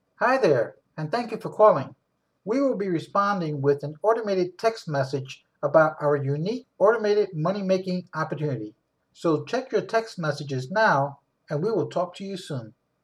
Your Auto-Reply Answering Audio Would Be: